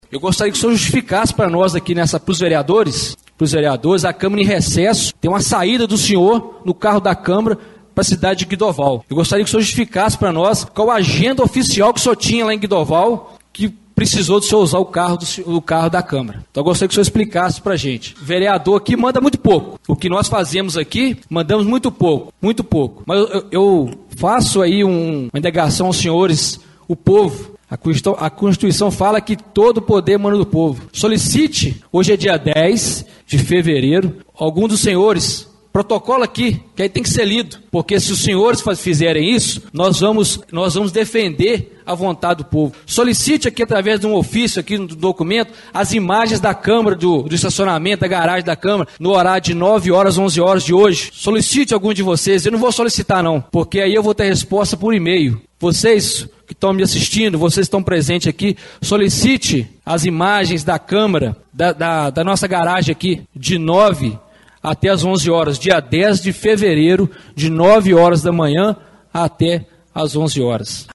Vereador José Roberto na tribuna declarou em tom de denúncia a suposta irregularidade